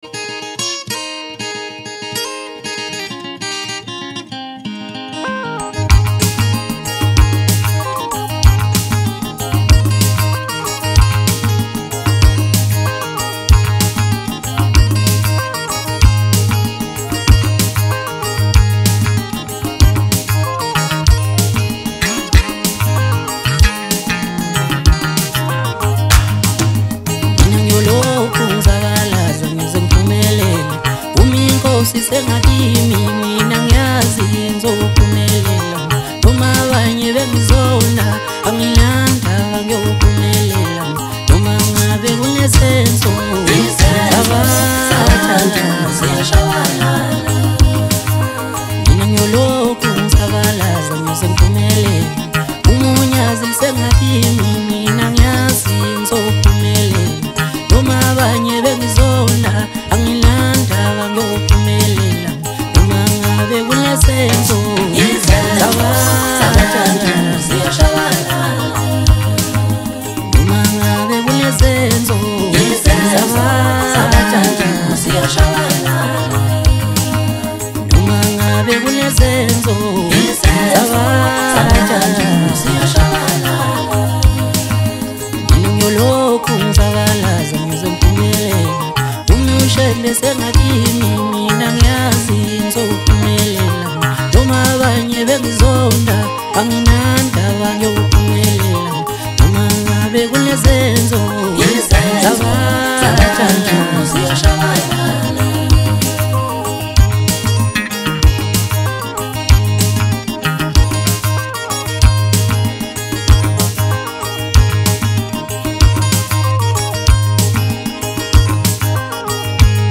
Maskandi, DJ Mix, Hip Hop